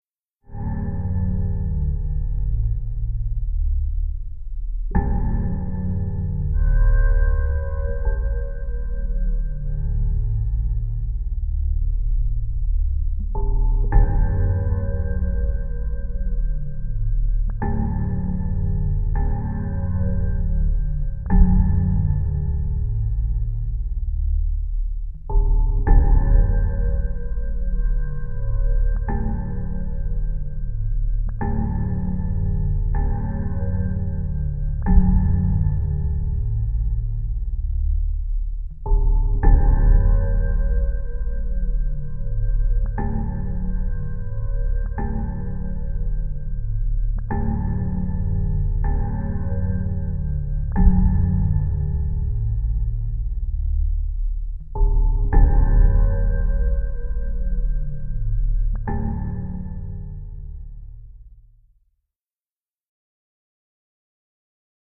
Cavernous Deep Cave Ambience with Light Rhythmic Elements Cave, Cavernous, Rhythmic